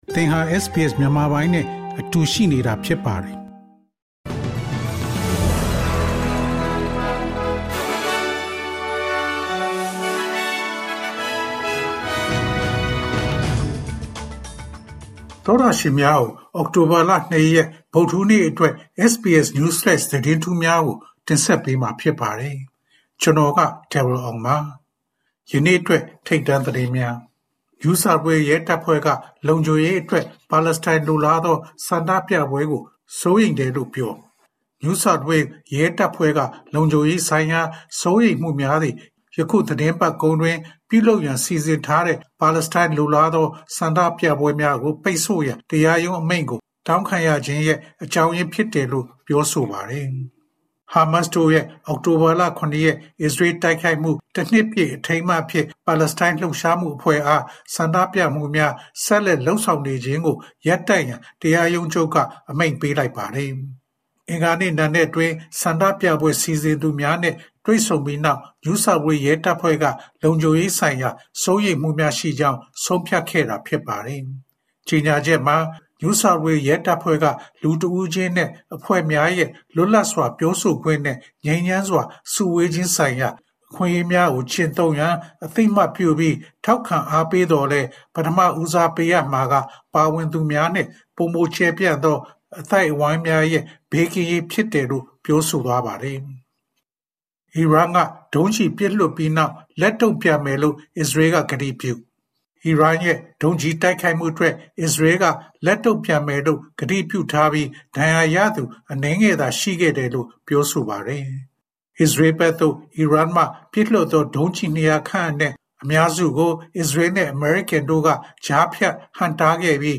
ALC: SBS မြန်မာ အောက်တိုဘာလ ၂ ရက် News Flash သတင်းများ။